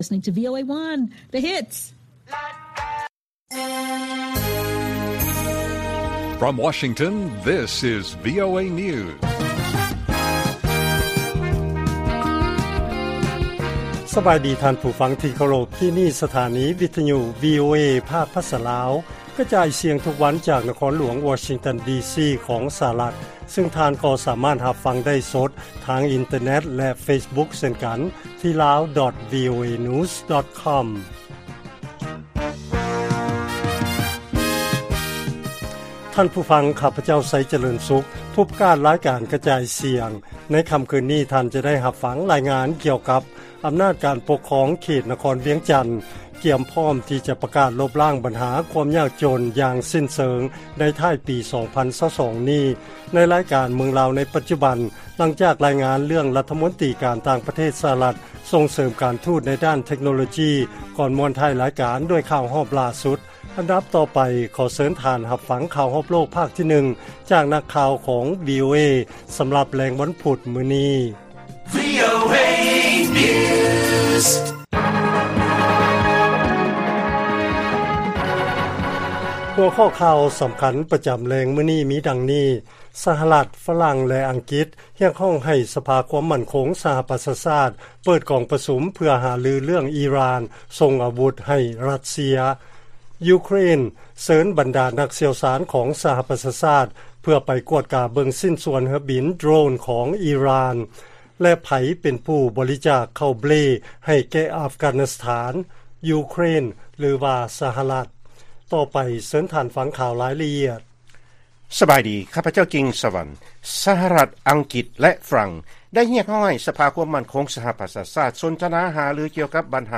ລາຍການກະຈາຍສຽງຂອງວີໂອເອ ລາວ: ສະຫະລັດ ຝຣັ່ງ ແລະອັງກິດ ຮຽກຮ້ອງໃຫ້ສະພາຄວາມໝັ້ນຄົງສະຫະປະຊາຊາດ ເປີດປະຊຸມເລື້ອງອີຣ່ານ